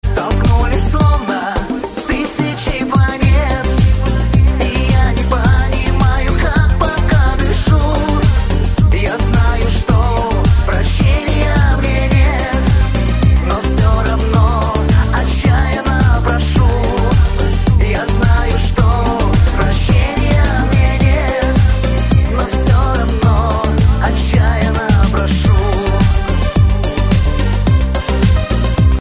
русский шансон